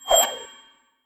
nut_fly_03.ogg